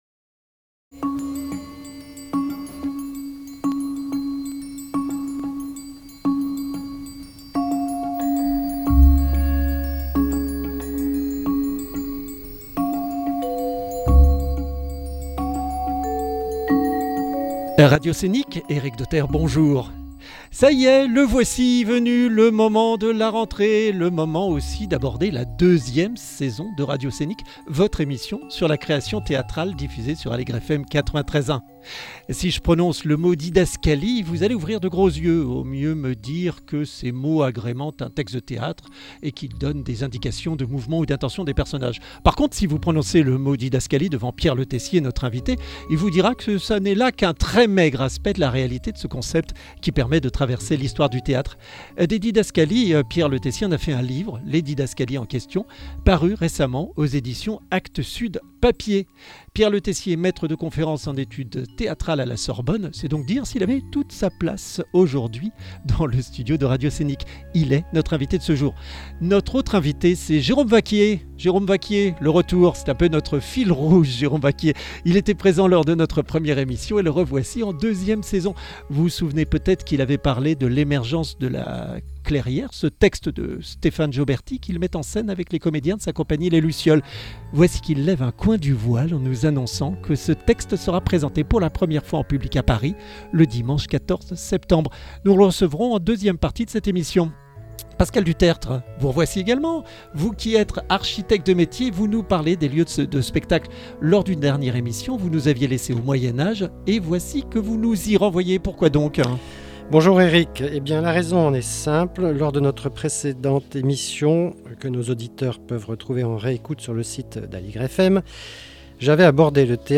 Nos éléments sonores et musicaux